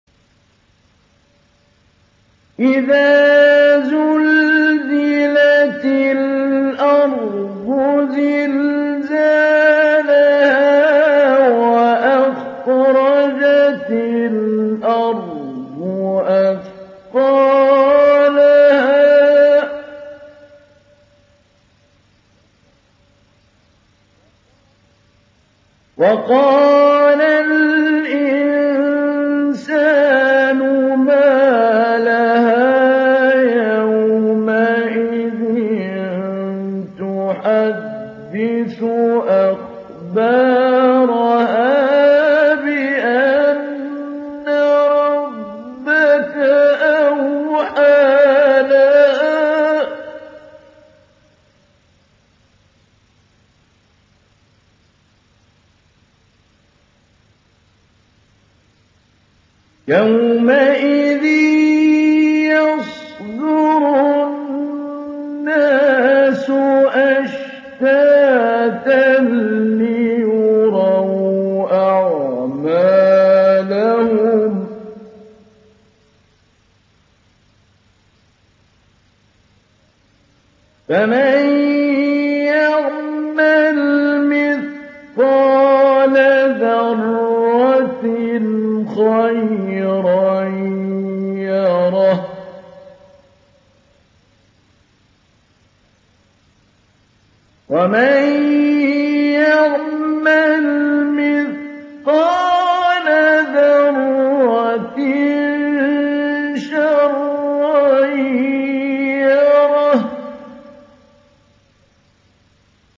Download Surat Az Zalzala Mahmoud Ali Albanna Mujawwad